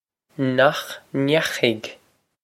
Pronunciation for how to say
Nokh nyakh-ig?
This is an approximate phonetic pronunciation of the phrase.